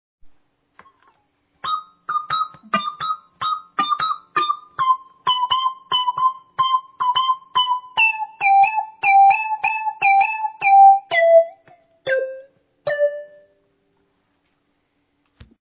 Zkusil jsem to na kombu Peavey řady VYPYR a neodolal jsem.
Akorát bacha na zpětnou vazbu, to kombo umí fakt ošklivě hvízdnout, je